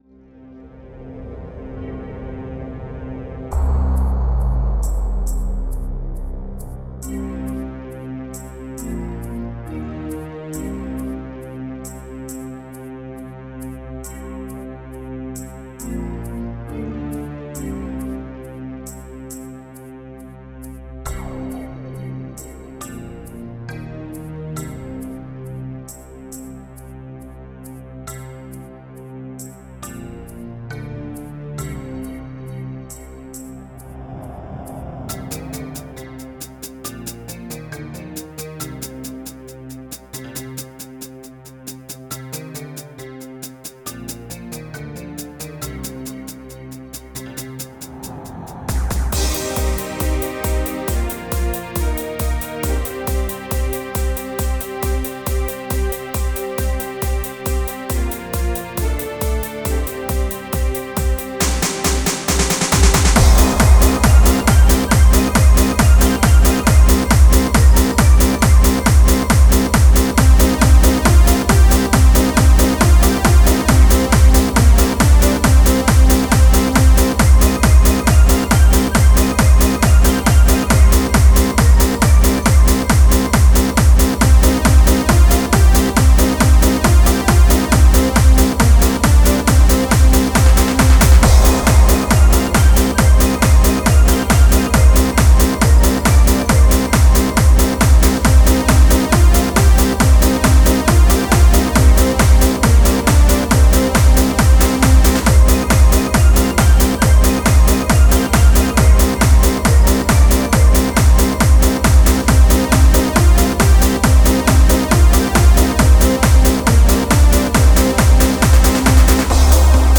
Dance-Arrangement